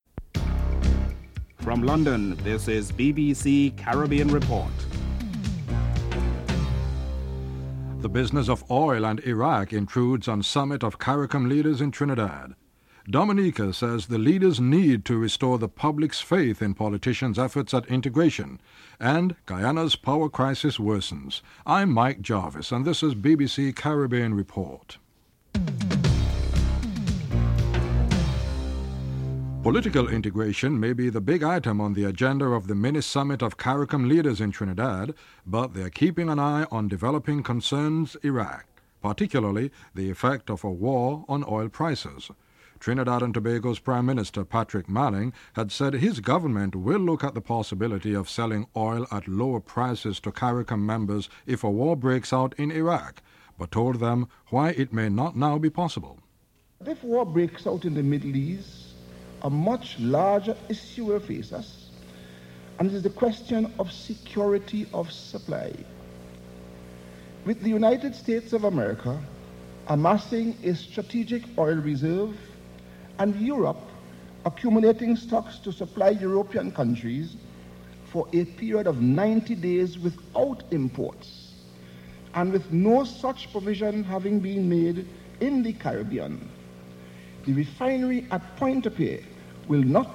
Headlines
David Blunkett, Home Office Secretary explains the seriousness of the security alert at Gatwick.